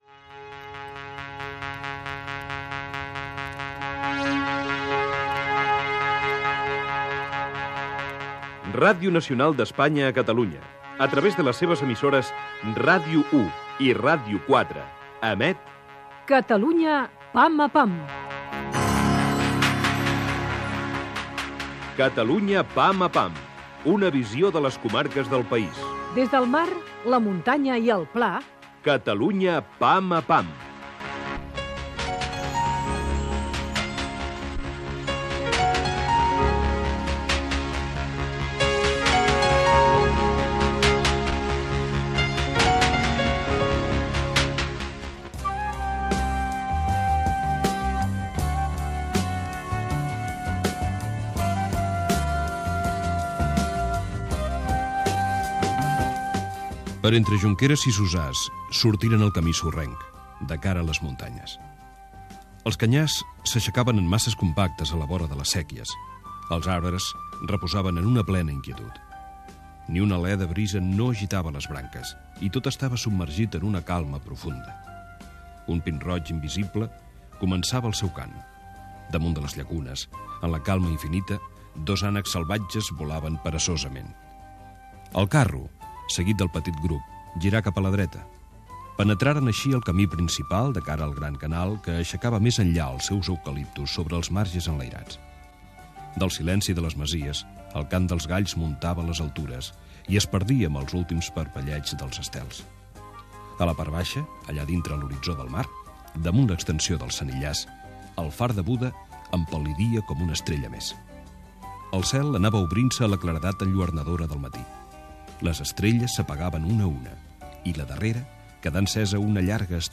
Careta del programa
lectura d'un fragment de la novel·la "Terres de l'Ebre"